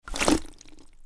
دانلود آهنگ آب 79 از افکت صوتی طبیعت و محیط
جلوه های صوتی
دانلود صدای آب 79 از ساعد نیوز با لینک مستقیم و کیفیت بالا